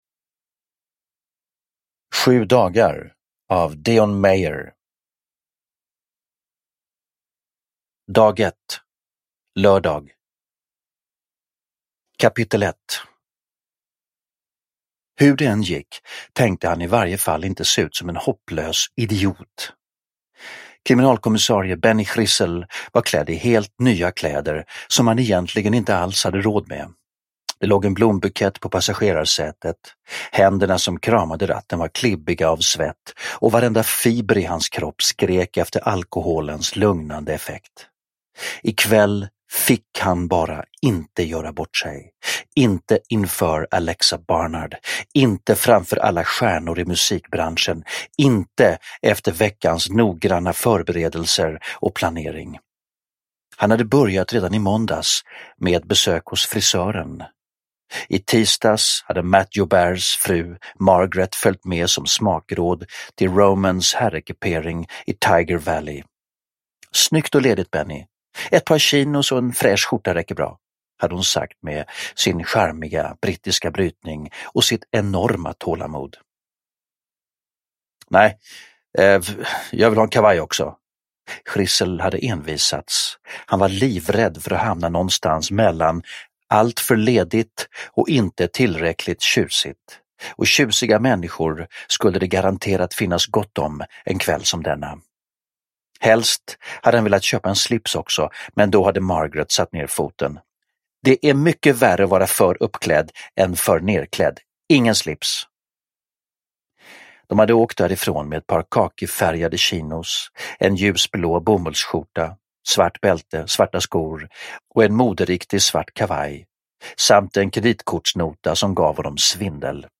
7 dagar – Ljudbok – Laddas ner
Uppläsare: Stefan Sauk